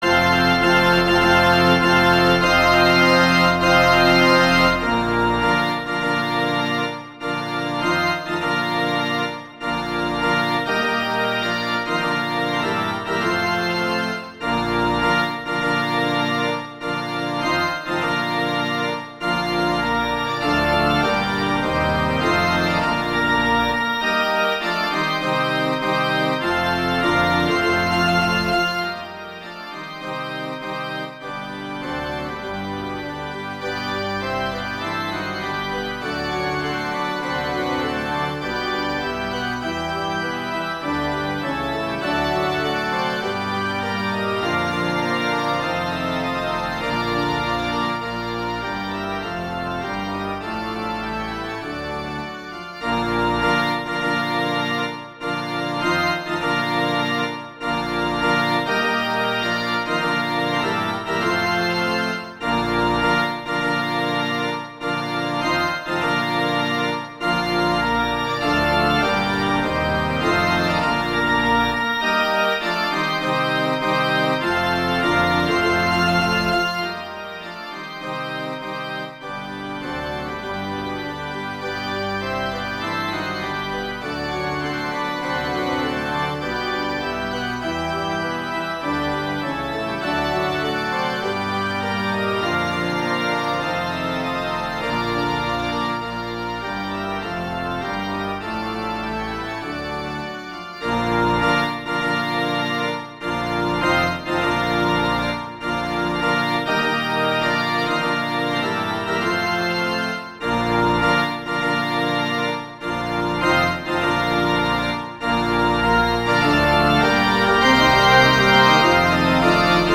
Instrumentation: organ solo
arrangements for organ solo